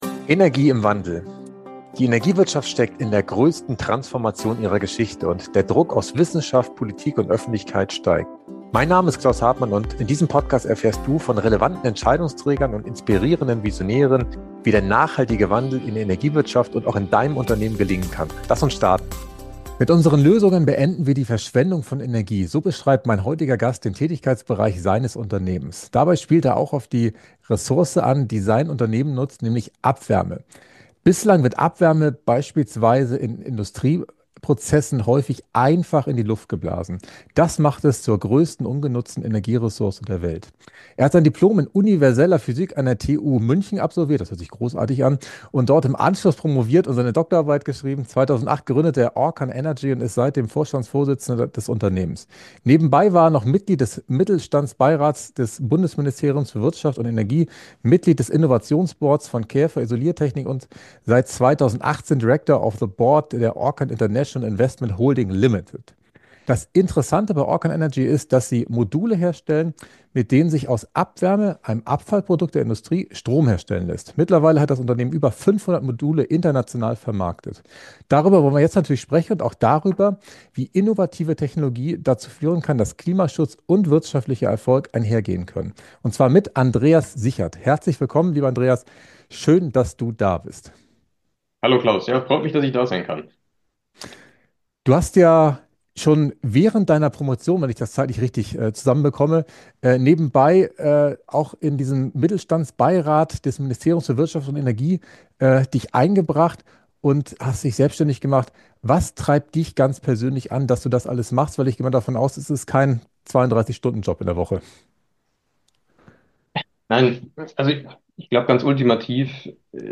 Im Podcast "Energie im Wandel" führe ich inspirierende Interviews mit Entscheidungsträgern sowie visionären Experten, die aktiv zur Energiewende beitragen.